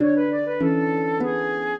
flute-harp
minuet6-5.wav